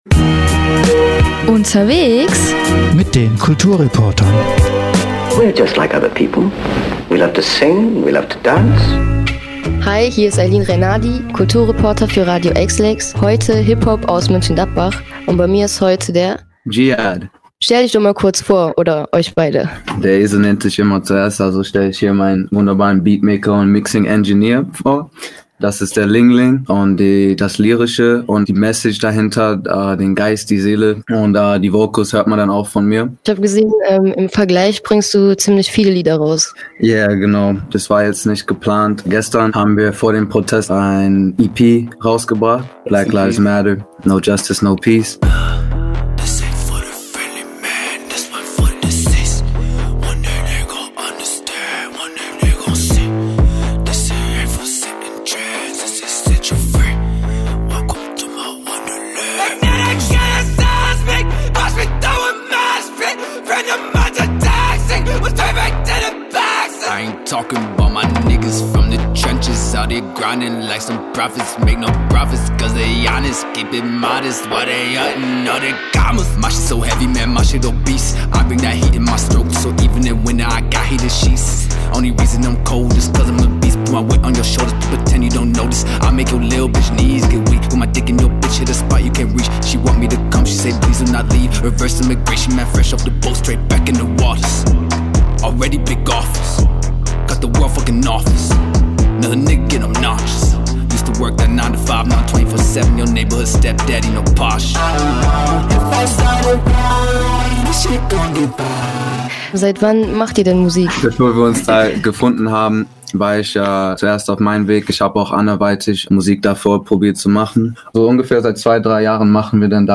Kulturreporter*inMusik aus MG
Darüber hinaus hört Ihr einige Ausschnitte seiner Songs.